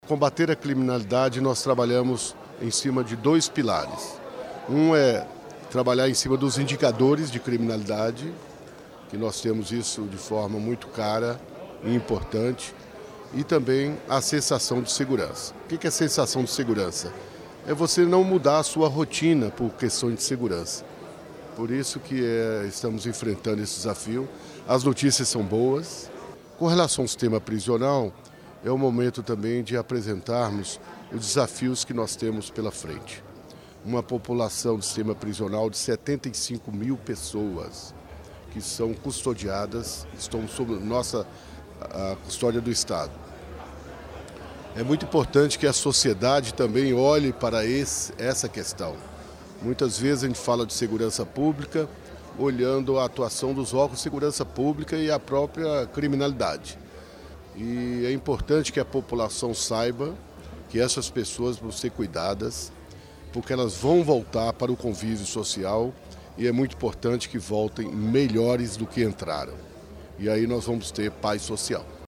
O secretário de Estado de Segurança Pública e Administração Prisional de Minas Gerais, general Mário Araújo, esteve em Juiz de Fora participando da “1ª Semana de Segurança Pública” do município, realizada em parceria entre a Prefeitura de Juiz de Fora e a Câmara Municipal, nesta quinta-feira, 11.
general Mário Araújo